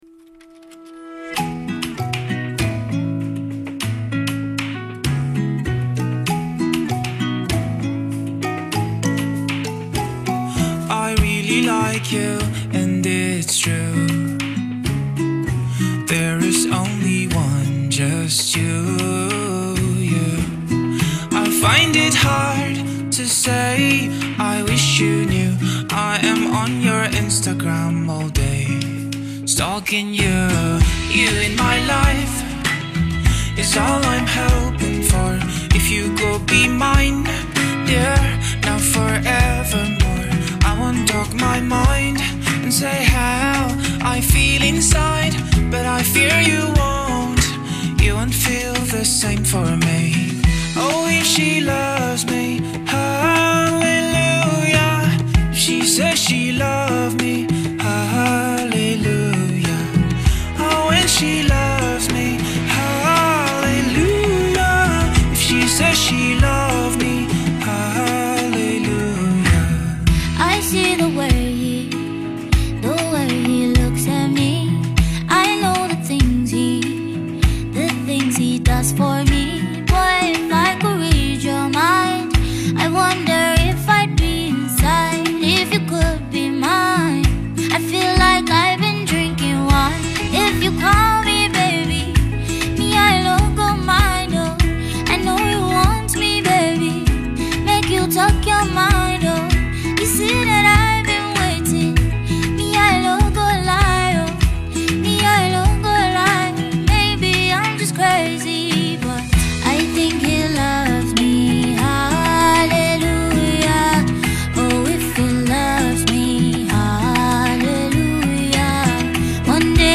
On this love infused banger